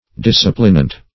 Disciplinant \Dis"ci*plin*ant\, n. [See Discipline.] (Eccl.